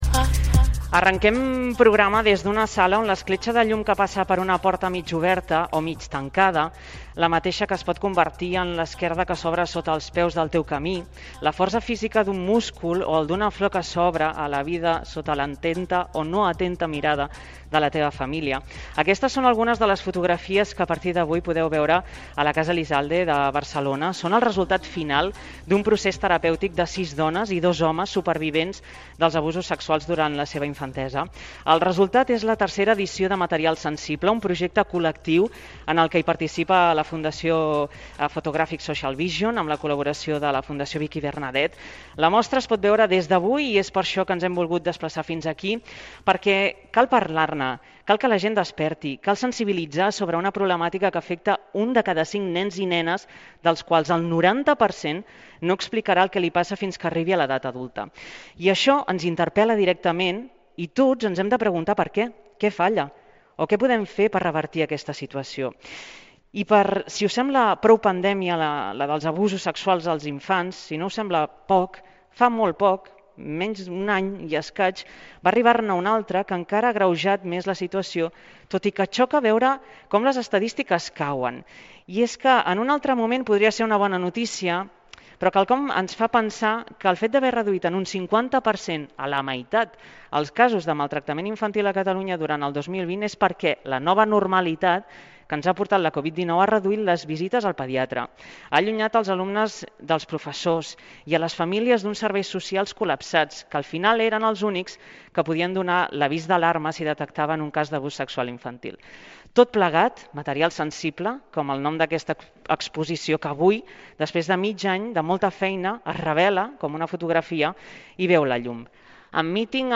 Reportatge sobre la mostra de fotografies del projecte "Material sensible" a la Casa Elizalde de Barcelona Gènere radiofònic Info-entreteniment